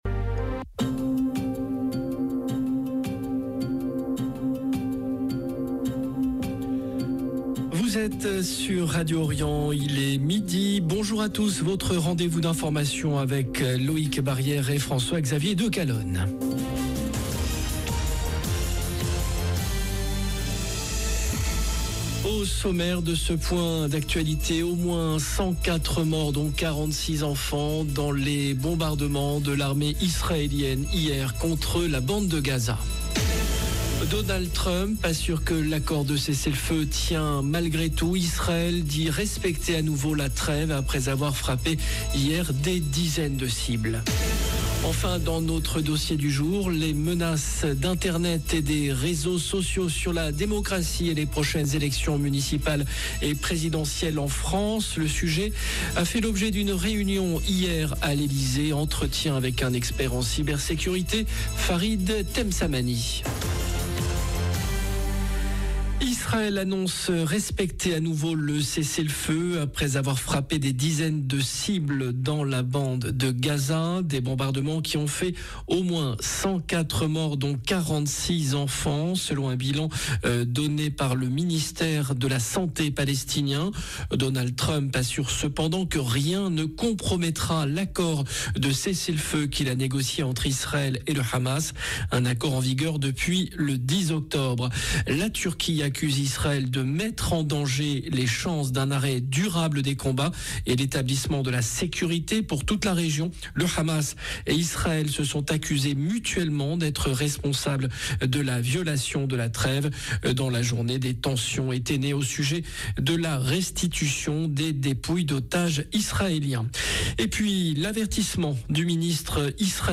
JOURNAL DE MIDI
Entretien avec un expert en cyber sécurité